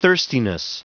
Prononciation du mot thirstiness en anglais (fichier audio)
thirstiness.wav